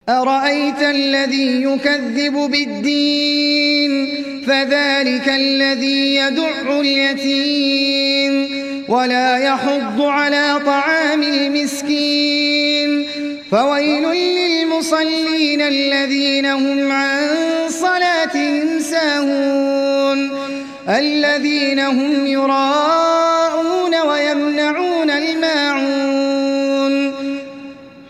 সূরা আল-মা‘ঊন ডাউনলোড mp3 Ahmed Al Ajmi উপন্যাস Hafs থেকে Asim, ডাউনলোড করুন এবং কুরআন শুনুন mp3 সম্পূর্ণ সরাসরি লিঙ্ক